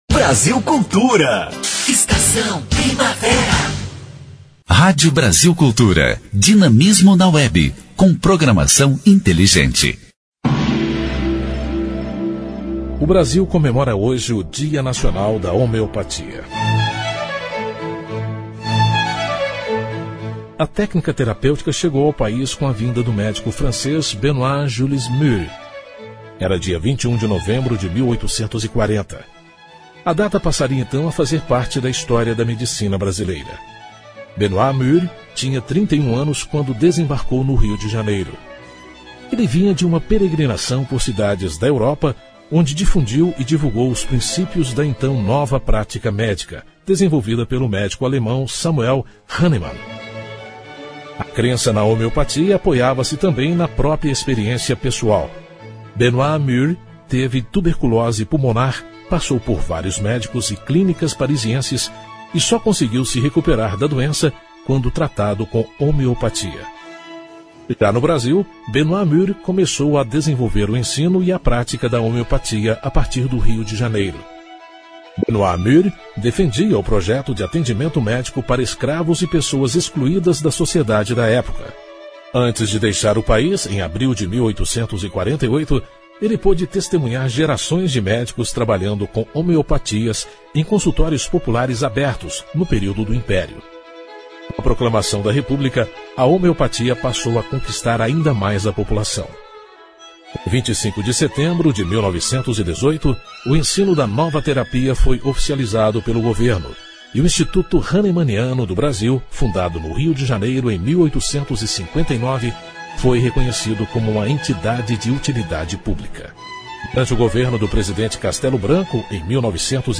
História Hoje: Programete sobre fatos históricos relacionados às datas do calendário. Vai ao ar pela Rádio Brasil Cultura de segunda a sexta-feira.